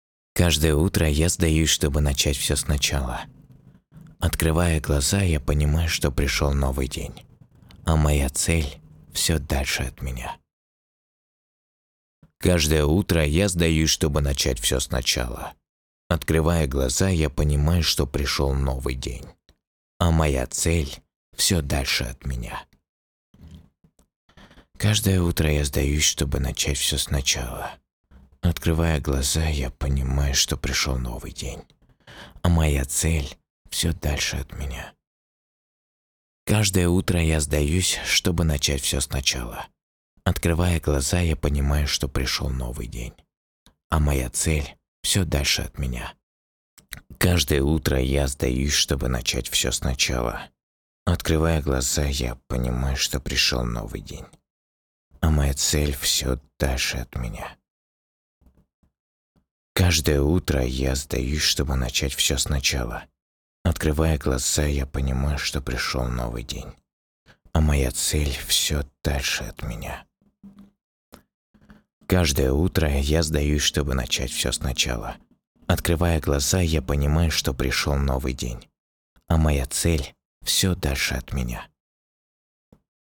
пародии
Муж, Пародия
Звуковая карта focusrite solo 3rd, микрофон se electronics x1 s